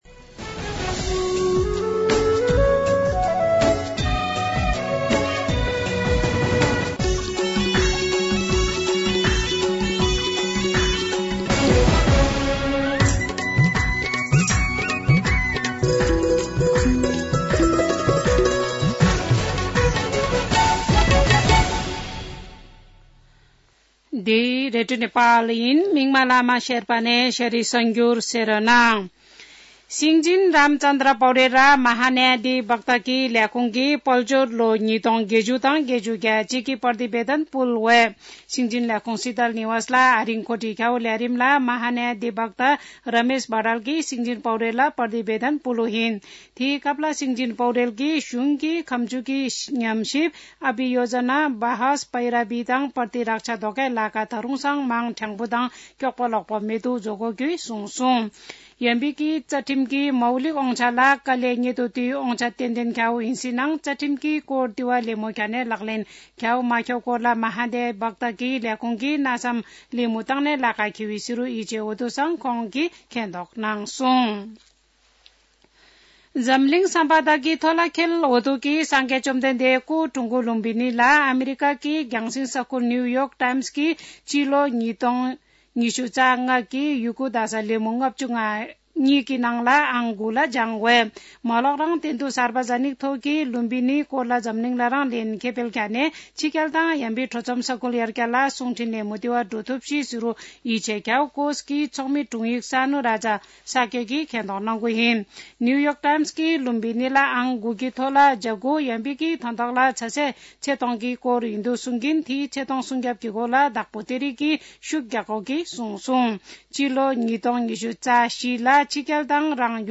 शेर्पा भाषाको समाचार : २९ पुष , २०८१
Sherpa-news-4.mp3